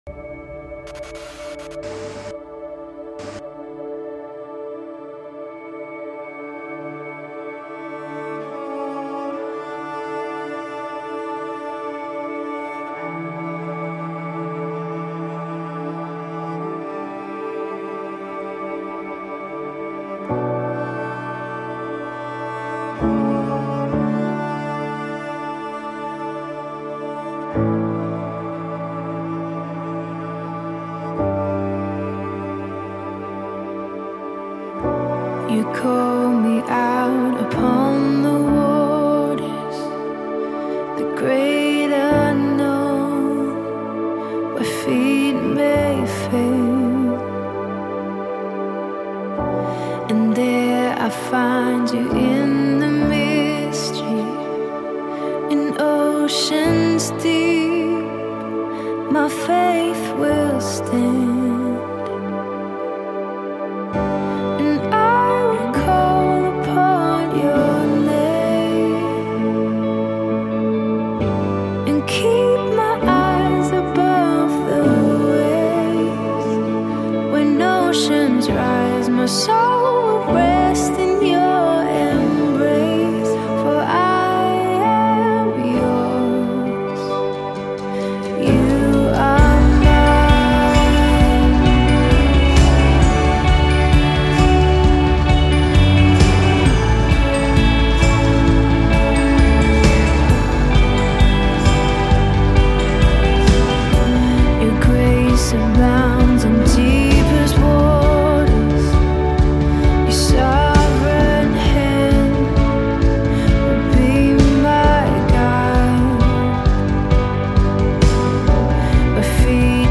American Gospel Songs